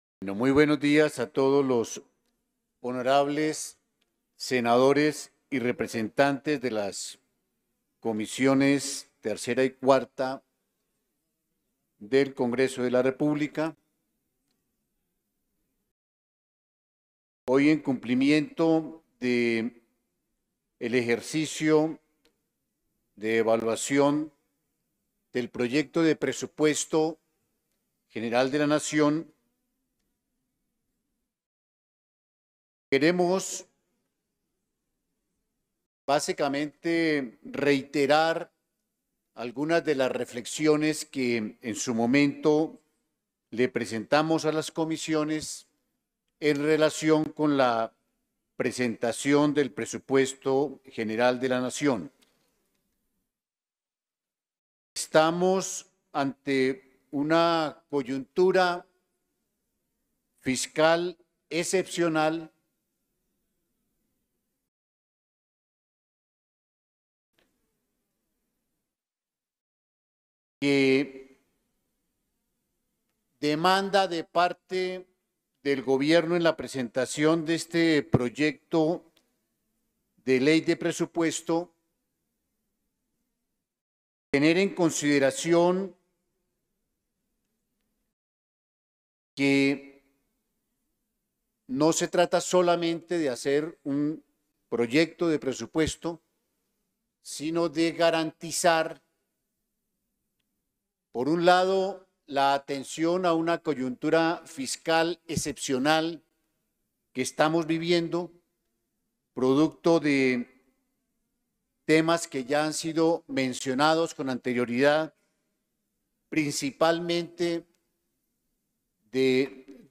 Intervención del ministro en las Comisiones Económicas Conjuntas
intervencion-del-ministro-de-hacienda-en-las-comisiones-economicas-conjuntas-septiembre-11